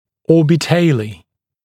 [ˌɔːbɪ’teɪliː] [ˌо:би’тэйли:] орбитале (Or) (цефалометрическая точка)